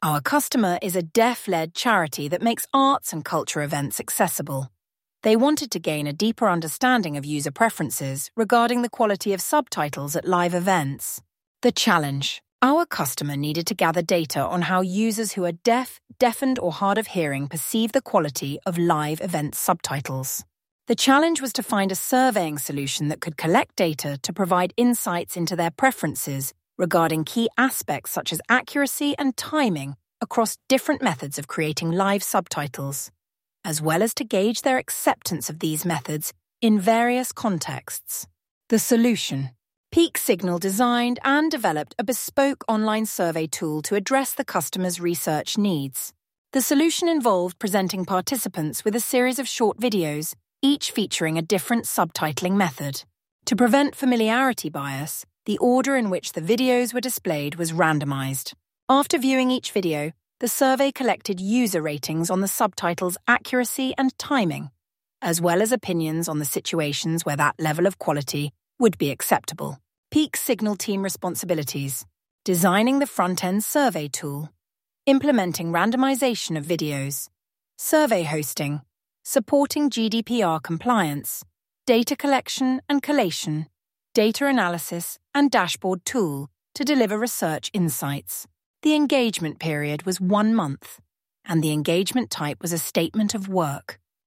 This audio provides a spoken overview of the User Preference Survey case study for visitors who prefer or require an auditory format.